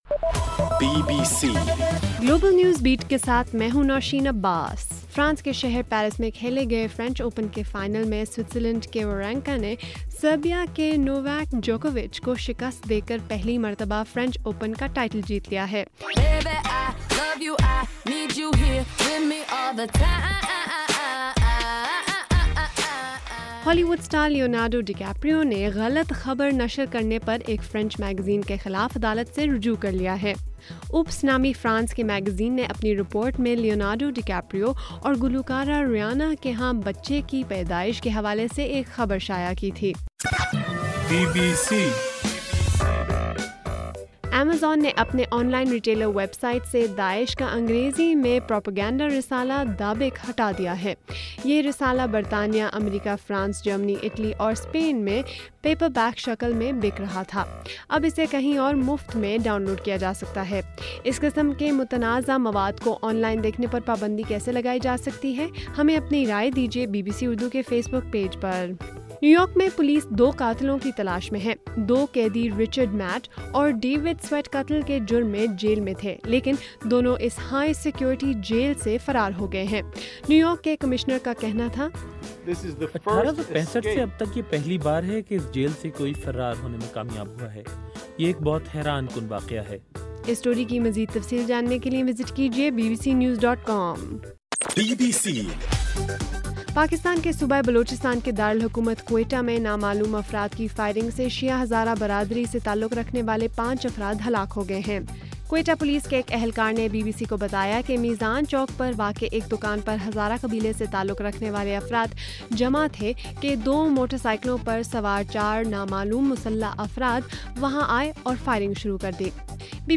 جون 8: صبح1 بجے کا گلوبل نیوز بیٹ بُلیٹن